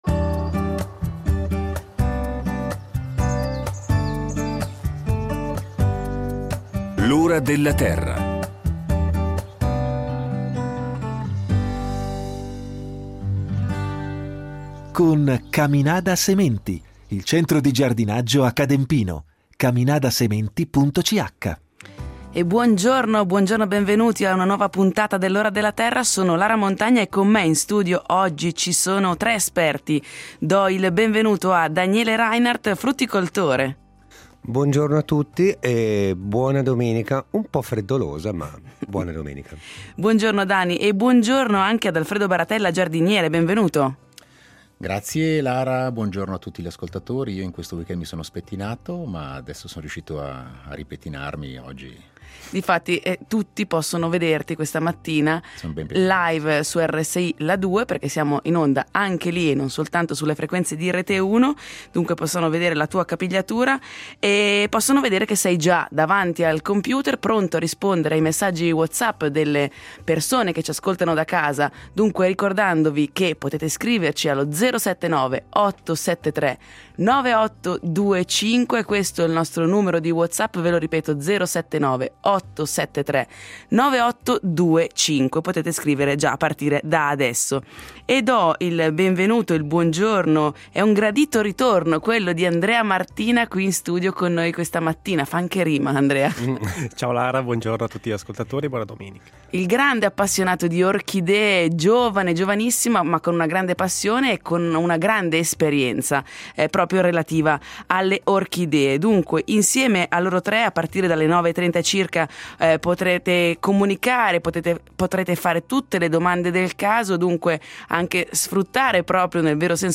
Non mancheranno gli esperti del programma che risponderanno in diretta alle domande del pubblico.